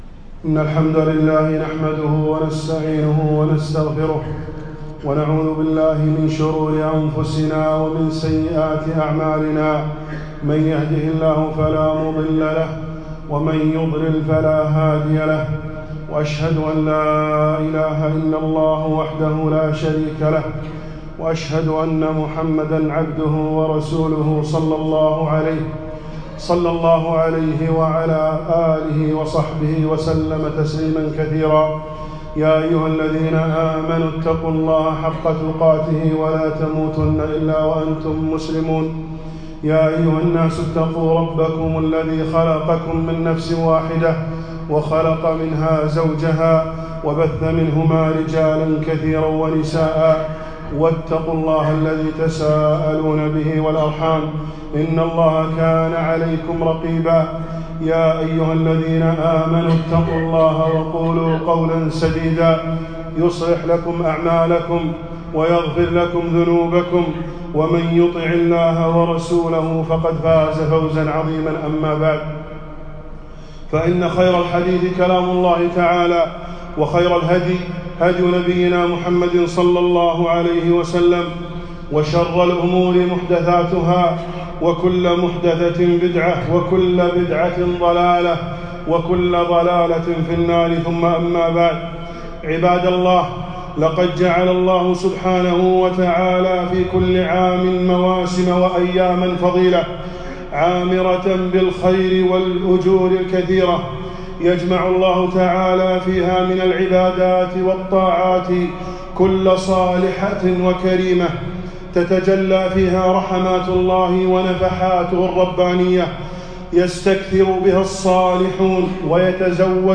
خطبة - فضل يوم عرفة والأضحى